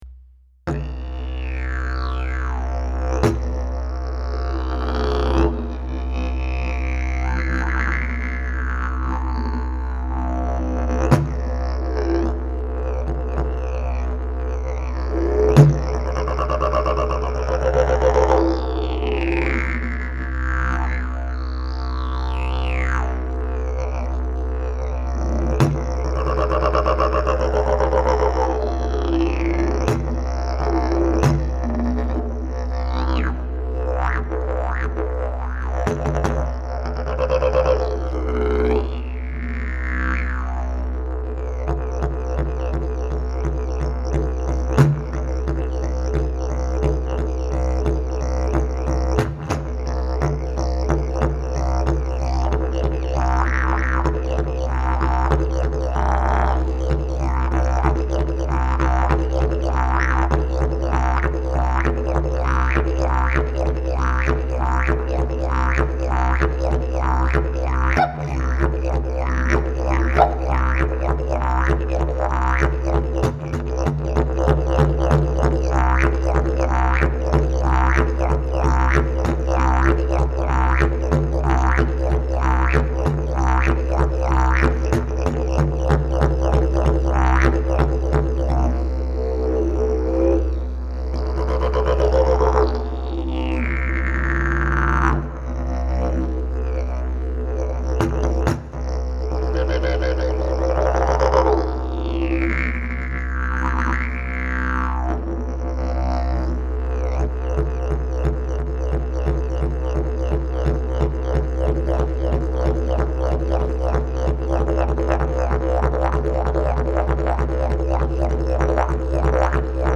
Key: C Length: 72" Bell: 5" Mouthpiece: Granadillo, Bloodwood, Red Zebrawood Back pressure: Very strong Weight: 5 lbs Skill level: Any
Didgeridoo #631 Key: C/B